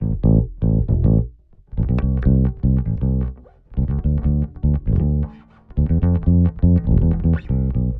31 Bass PT1.wav